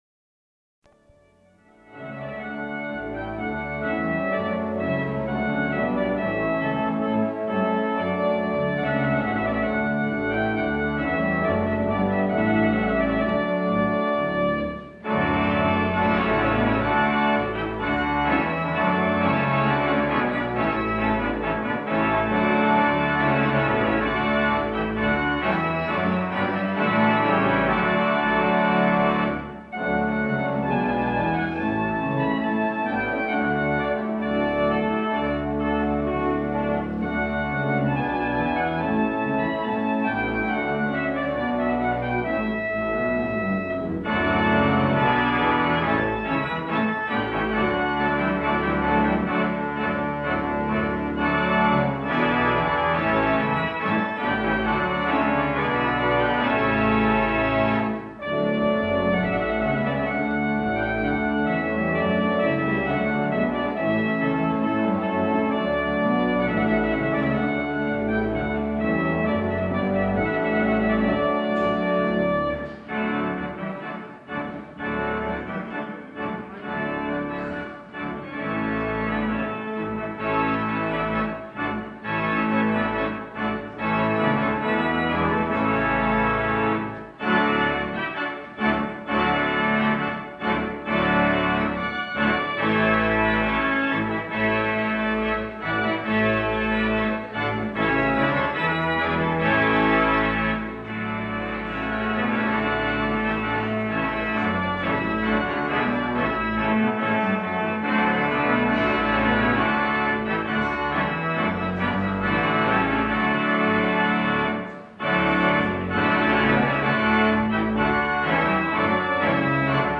In the archives we find an original tape recorded circa 1970 in California on a UHER portable machine. (4000 report-L, 7.5 “/sec, mono, half track) – Pizza & Pipes at the “Cap’ns Galley”.
web pics of the Uher recorder used in the late 1960’s for recording the Cap’ns Galley organ.